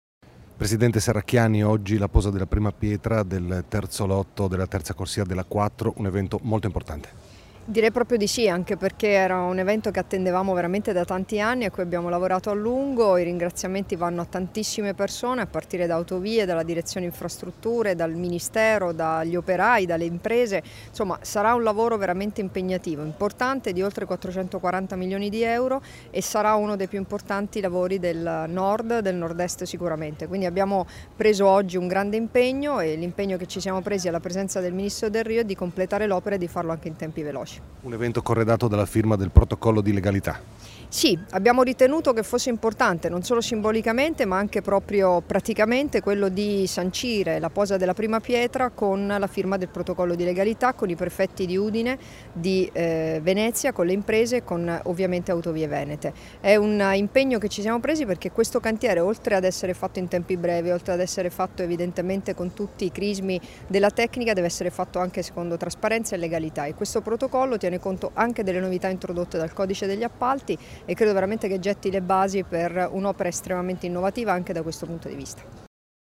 Dichiarazioni di Debora Serracchiani (Formato MP3) [1324KB]
a margine della cerimonia per la posa della prima pietra del terzo lotto della terza corsia dell'Autostrada A4 (tratto Alvisopoli-Gonars), rilasciate a Ronchis (UD) il 23 novembre 2016